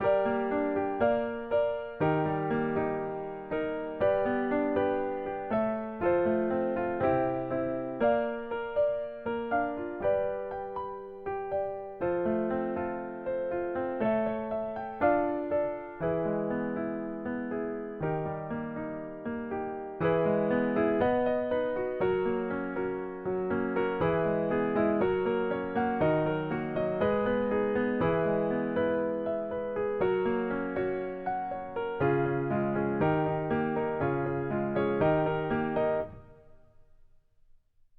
04_評価用ピアノ演奏.mp3